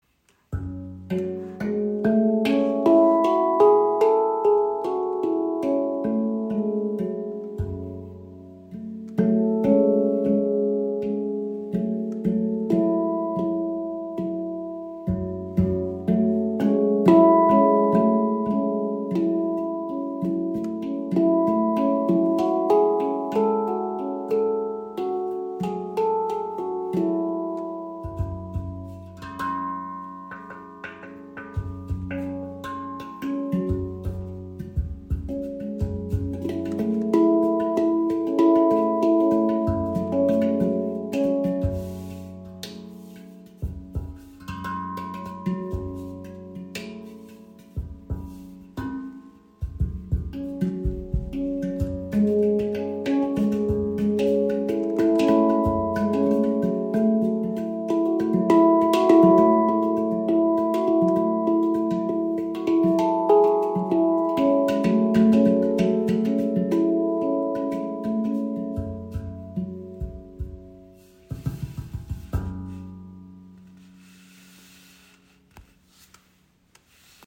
Handpan Noblet | F#2 Pygmy | 9 Klangfelder | Tiefe, pulsierender Kraft
• Icon F# Pygmy – tiefer, erdender Charakter F#2 – F# Ab A C# E F# Ab
• Icon Edelstahl-Handpan – langer Sustain, klare Ansprache für meditative Musik
Sie erzeugt einen erdigen, rhythmisch fokussierten Klangraum, der zugleich ruhig und intensiv wirkt und das intuitive Spiel stark unterstützt.
F#2 Pygmy entfaltet eine erdige, archaische Klangwelt mit ruhiger Kraft und klarem Puls.
Die F#2 Pygmy Basshandpan entfaltet eine tiefe, erdige Klangwelt mit klarer rhythmischer Struktur und kraftvollem Fundament.